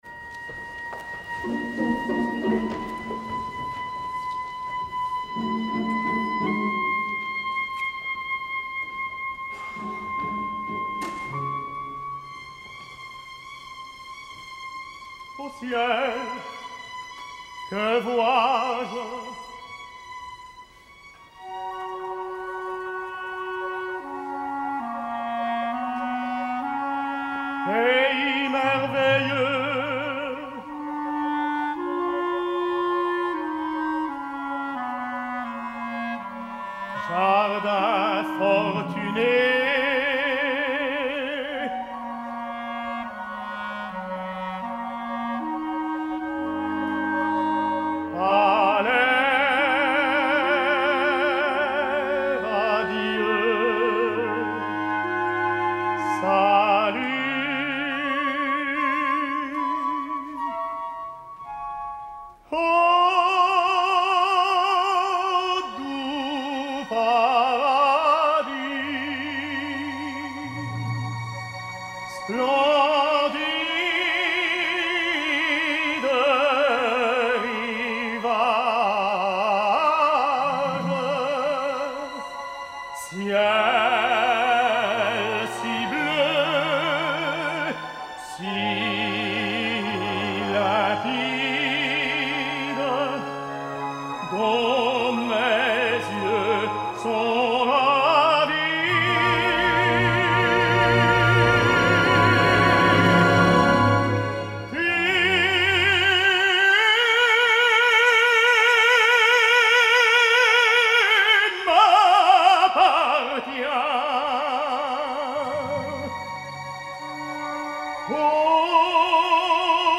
A Mazzola l’obra li pesa en excés i la fa feixuga, i això si en qualsevol òpera és problemàtic en les de Meyerbeer és letal.
De fet aquesta ha trigat molt a ser emesa , potser intentant buscar un mix entre vàries representacions.
Alagna està tibat i esgotat, obre les vocals buscant aire desesperadament, en un intent esforçat d’arribar a bon port, però aquesta entrega que l’honora i el caracteritza s’endu per endavant la línia i l’elegància del cant francès, i el seu Meyerbeer resulta quasi com un verisme mal entès.
Vasco da Gama, Seeoffizier: Roberto Alagna
Chor und Orchester der Deutschen Oper Berlin
Deutsche Oper Berlin, 15 d’octubre de 2015